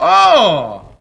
Worms speechbanks
bungee.wav